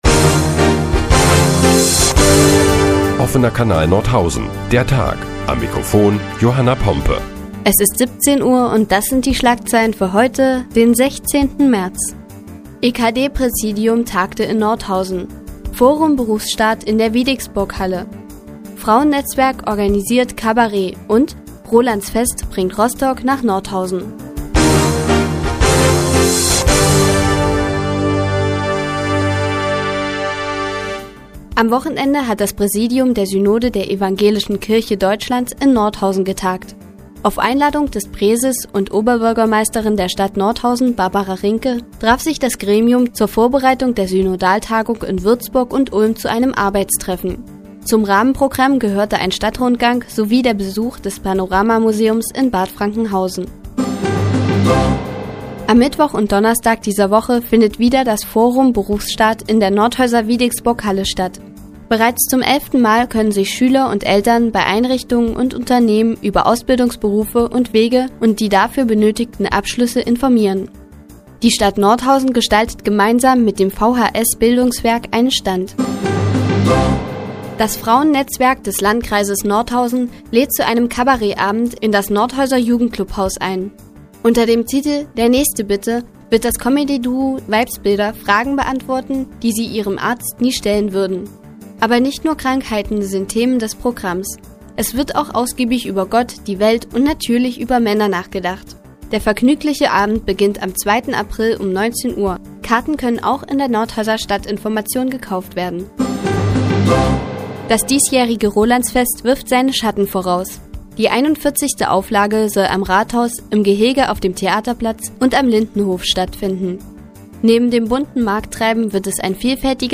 Die tägliche Nachrichtensendung des OKN ist nun auch in der nnz zu hören. Heute geht es unter anderem um das Comedy-Duo "Weibsbilder" und die ersten Ankündigungen fürs Rolandsfest.